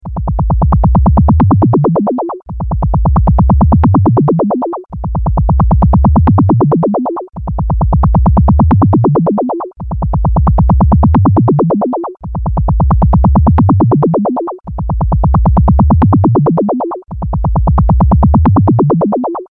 biuldingthump.mp3